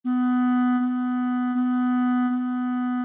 Piano (Corda percutida) | Orquestra de cARTón (ODE5)